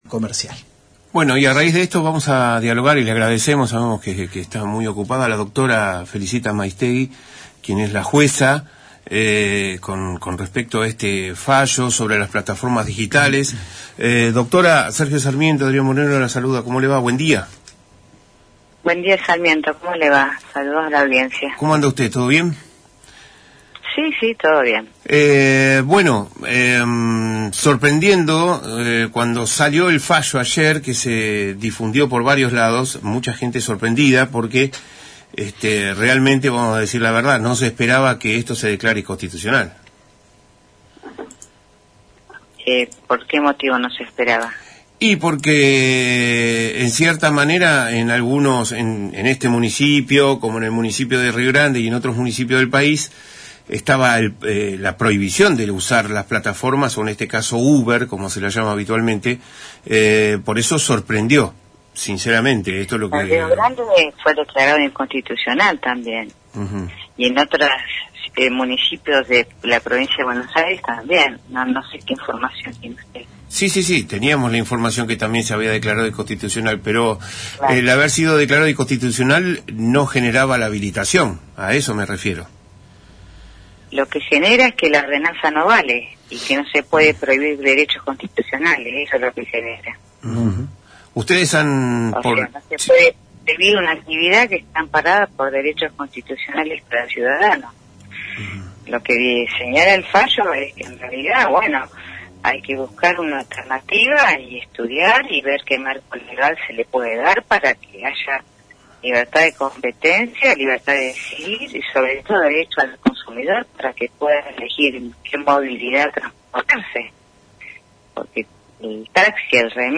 Las declaraciones de la Dra. Felicitas Maiztegui Marcó, fueron emitidas en Radio Provincia. Allí, se refirió al fallo que declara inconstitucional las ordenanzas anti-uber en Ushuaia.